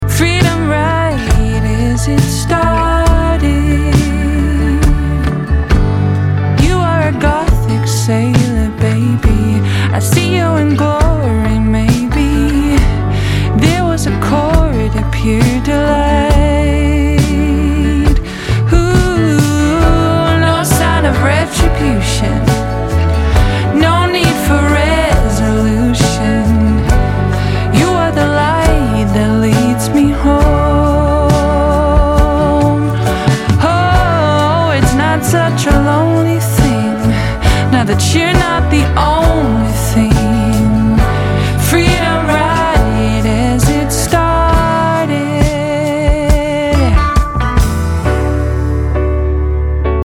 Indie Rock, Indie Pop >
Folk Rock, Acoustic >